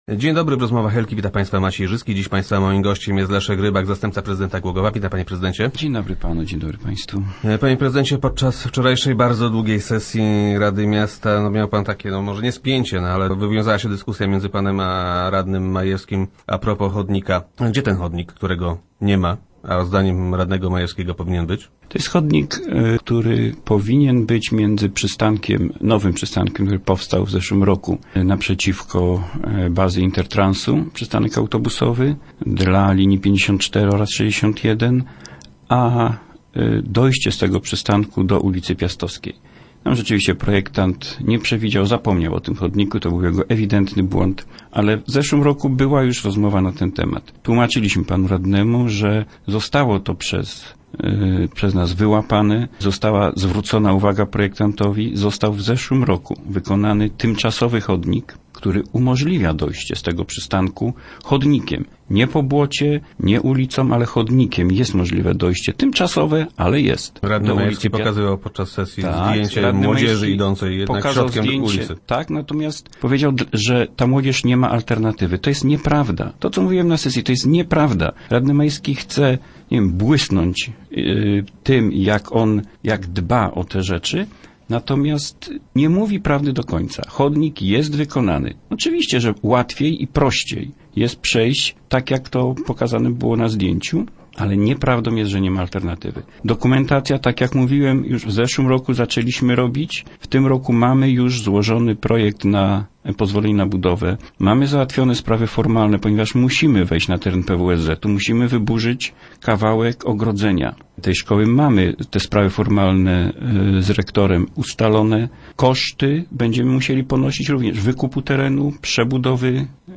Start arrow Rozmowy Elki arrow Rybak: Na razie pogoda nam sprzyja
- Mamy nadzieję, że nie będzie załamania pogody - mówi Leszek Rybak, zastępca prezydenta Głogowa.